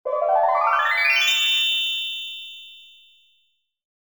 常用结尾音效-2
【简介】： 比较常见的结尾音效